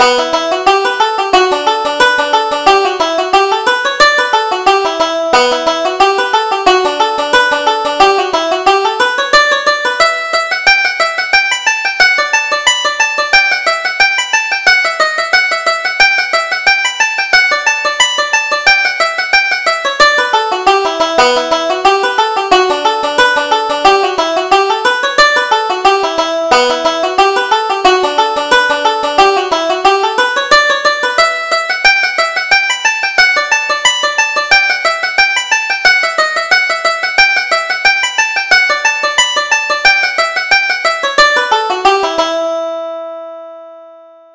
Reel: Murtough Molloy